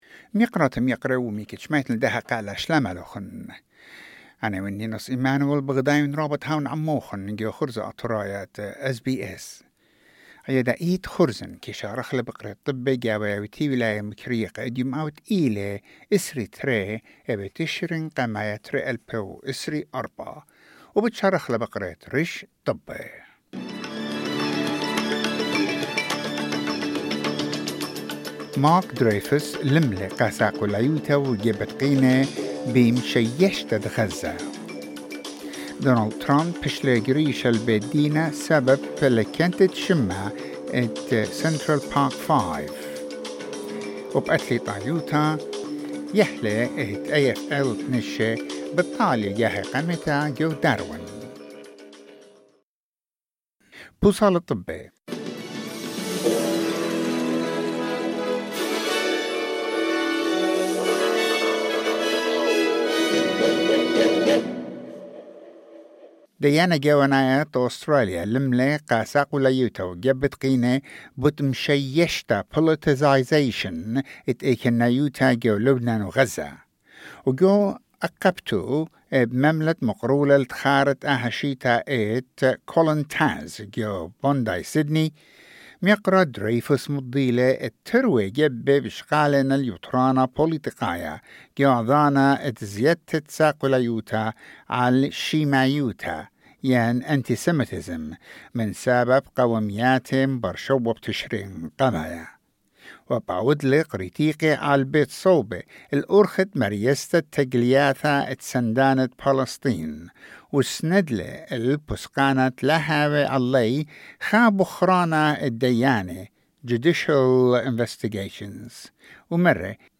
SBS Assyrian news bulletin: 22 October 2024